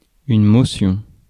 Ääntäminen
IPA : /ˌɹɛzəˈl(j)uːʃən/